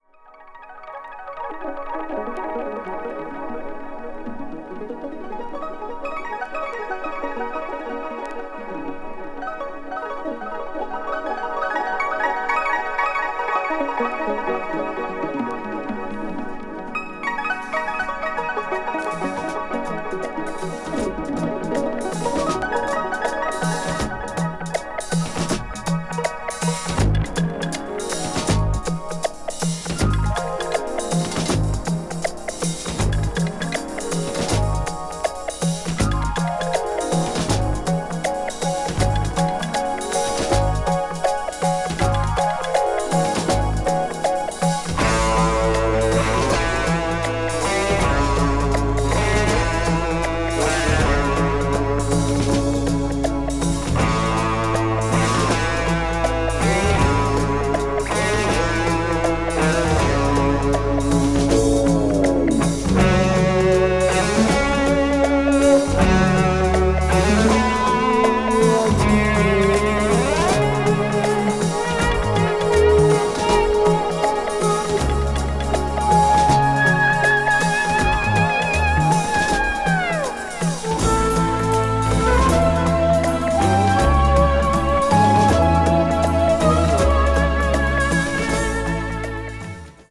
electronic   progressive rock   symphonic rock   synthesizer